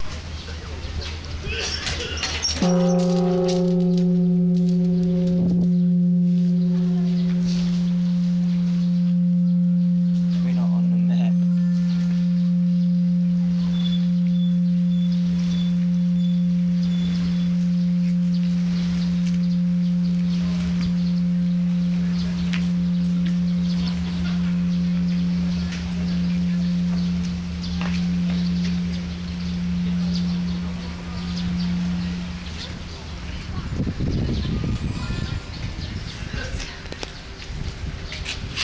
bell of peace
bell-of-peace.wav